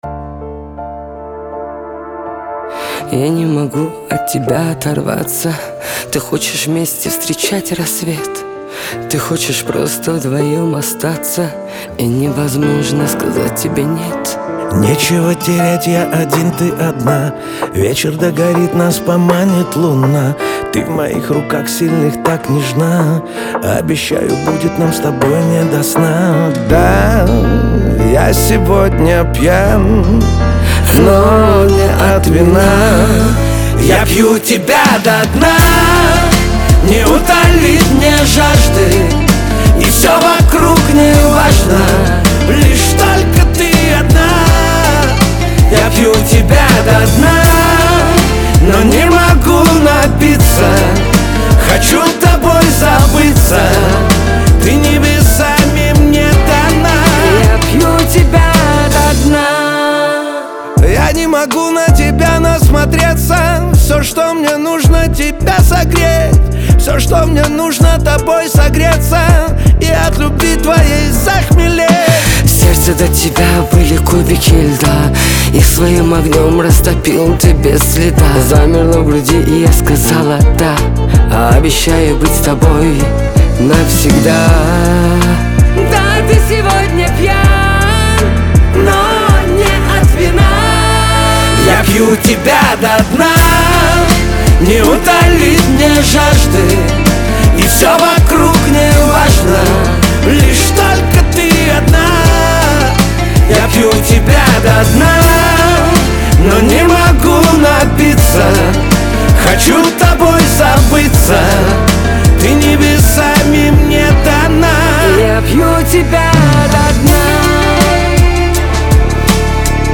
эстрада , pop
дуэт , диско